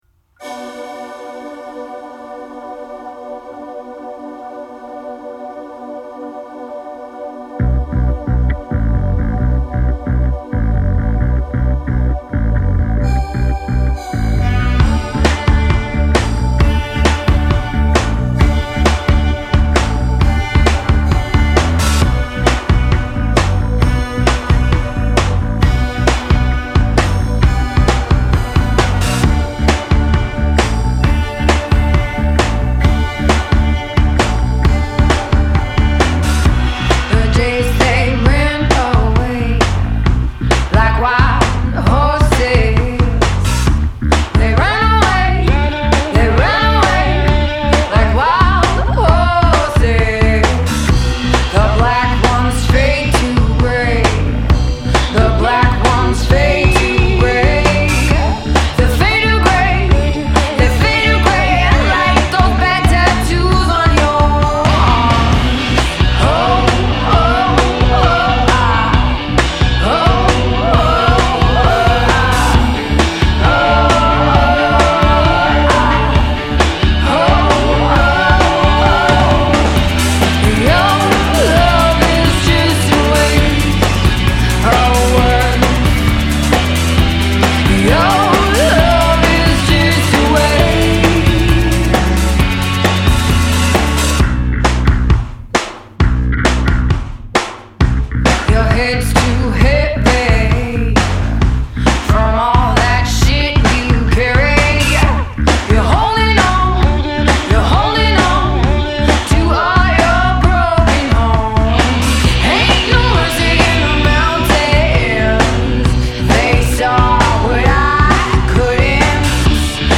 minor-key rocker w/ appealing swagger